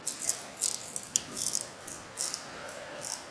落冰01
描述：一根冰柱松动了，从两层楼高的地方摔下来，砸在冰冻的冰面上。 立体声，使用MBox Micro II录音机和标准的"T"麦克风录制。
标签： 氛围 碰撞 坠落 冰柱 粉碎
声道立体声